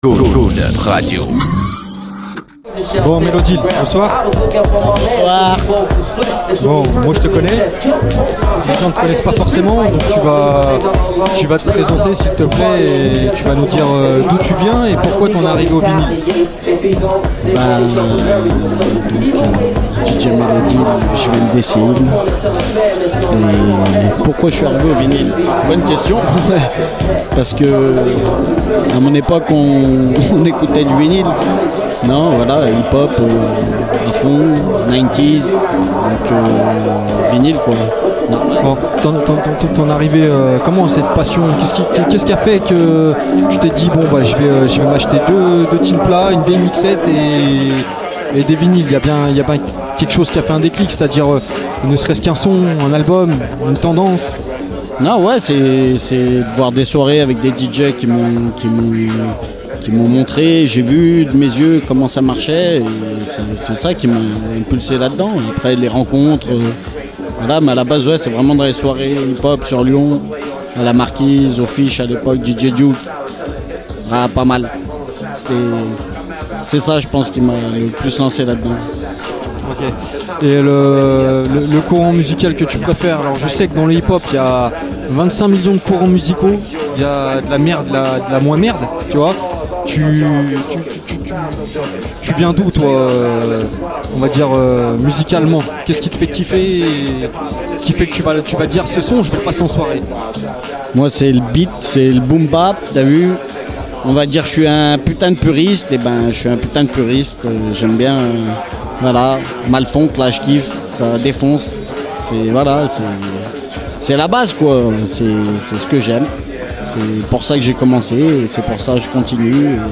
Wrecks On Weekend Report – Interview
au Ninkasi dans le cadre du disquaire Day!